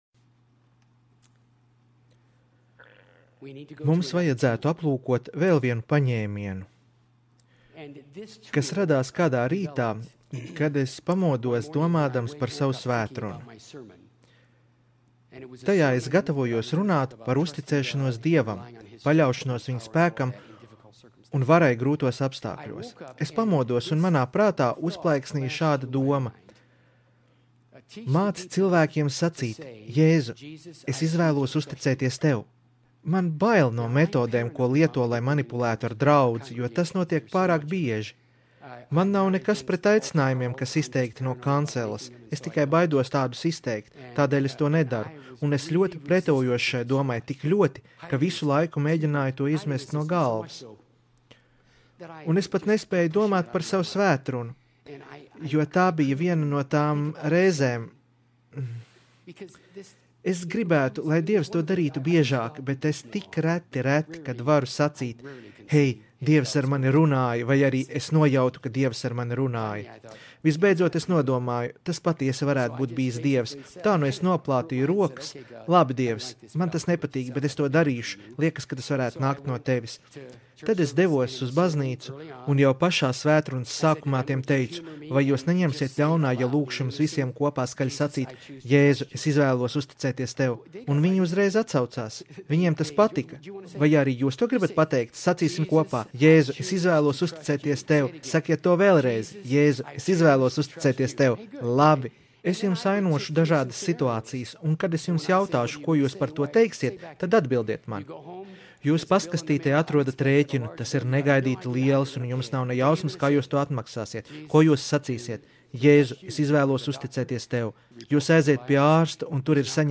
Skatīties video Seminārs - Kā pareizi nomirt... un dzīvot, lai par to stāstītu!